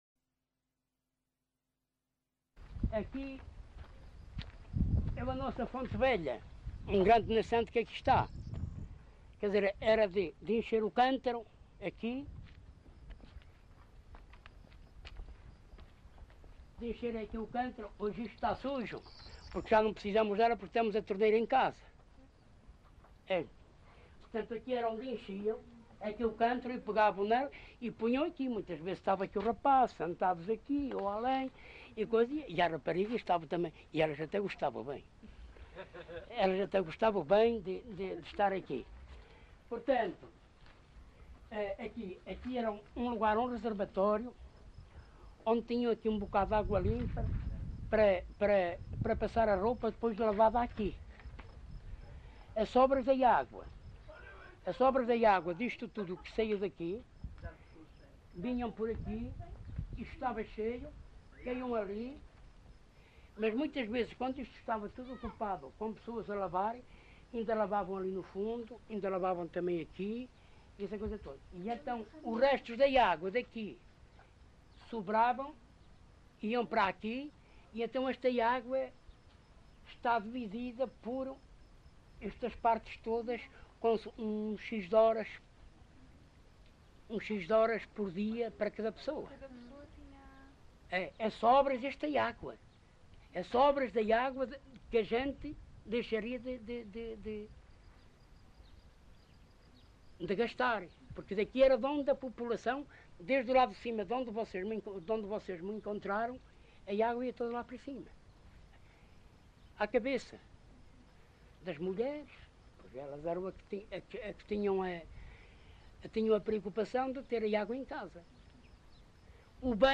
LocalidadePerafita (Alijó, Vila Real)